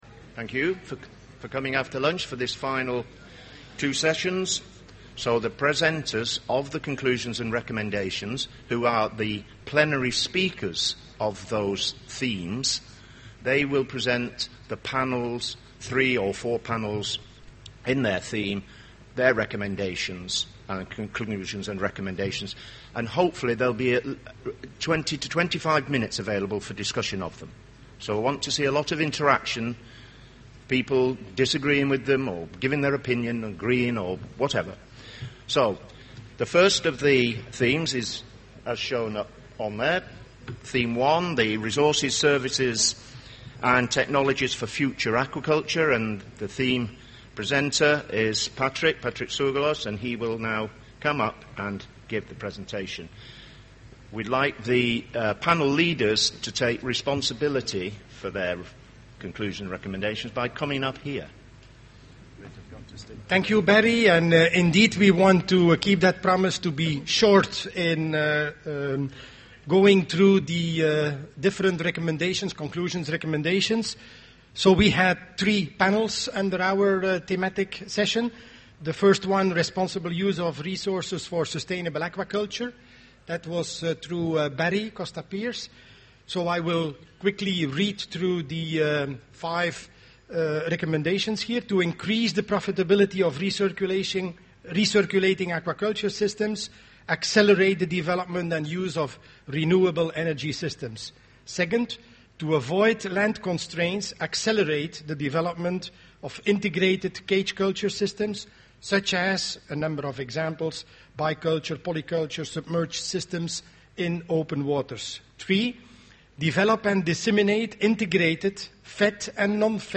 Presentation of the summary, conclusions and recommendations of Thematic Session 1: Resources, services and technologies for future aquaculture.